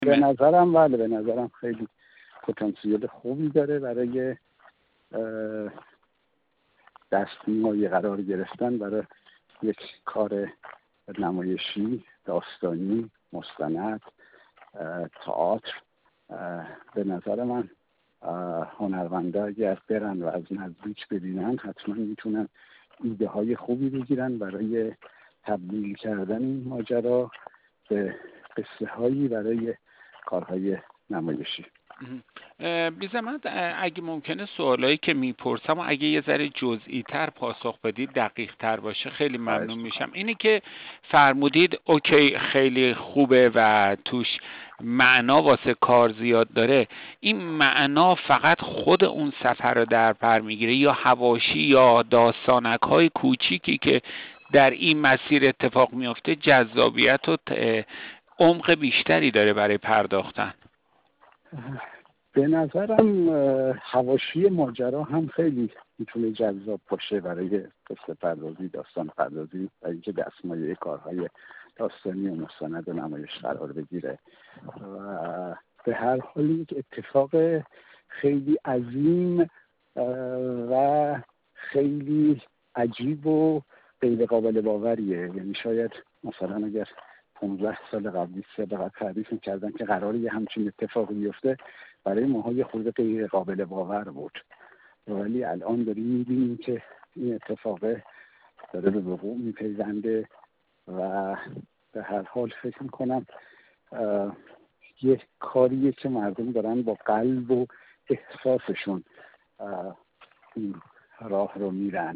با این مدیر و سینماگر پیرامون ظرفیت‌های نمایشی راهپیمایی اربعین خبرنگار ایکنا گفت‌و‌گویی انجام داده که در ادامه با آن همراه می‌شویم.